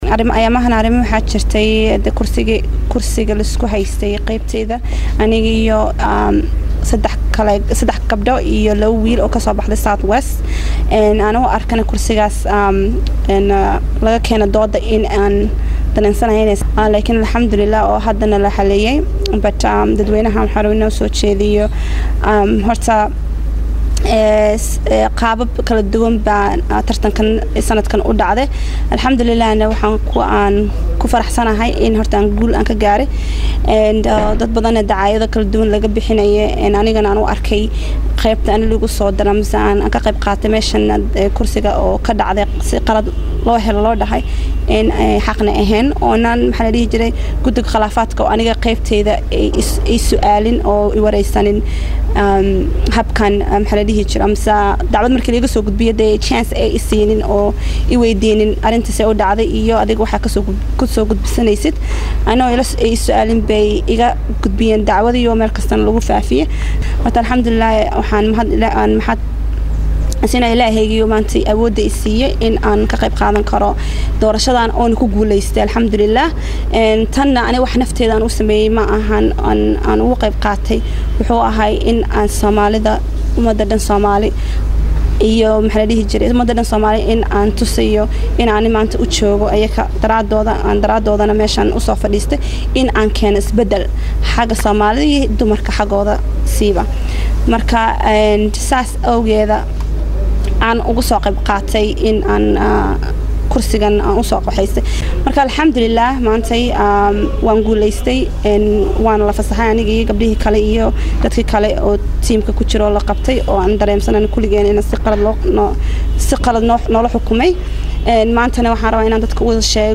Baydhabo(INO)- Xildhibaan Muna Sheekh Khaliif Abuu oo ka mid ah Mudanayaasha BFS Ee laga Soo Doortey Koonfur Galbeed Soomaaliya ayaa ka hadashey Go’aankii Gudiga Xalinta Khilaafaadka Ee Soomaaliya, iyadoona  Sheegdey in Mar kale Gudiga ay ka noqdeen Diidmadooda.